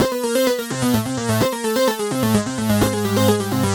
Index of /musicradar/french-house-chillout-samples/128bpm/Instruments
FHC_Arp C_128-C.wav